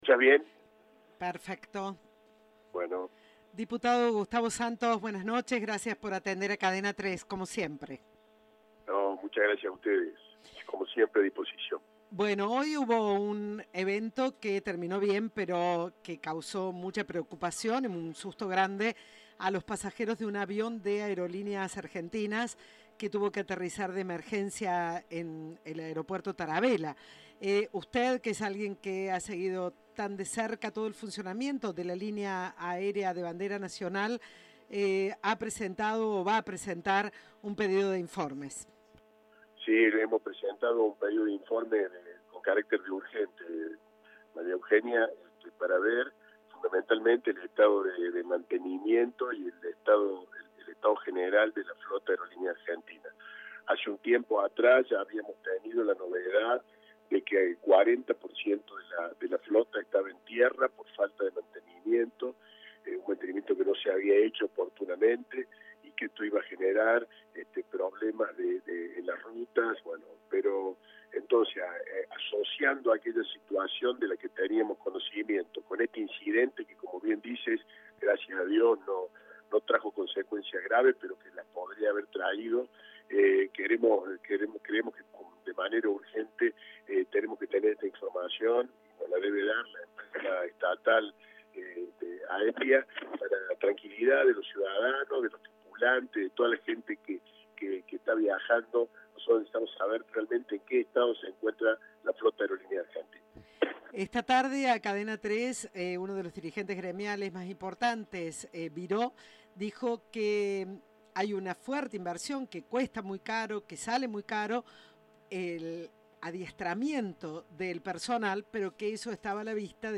"No hay duda de que ha habido una maniobra adecuada, pero en esto no puede haber medias tintas. La seguridad es lo básico y Aerolíneas es un empresa que le cuesta dos millones de dólares por día a los argentinos y necesitamos saber en qué estado está", profundizó a Cadena 3 el diputado.